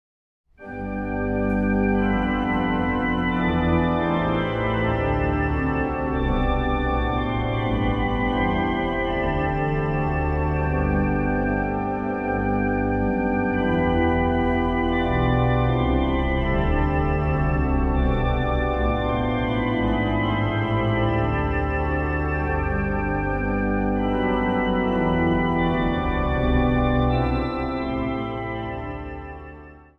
Instrumentaal | Dwarsfluit
Instrumentaal | Hobo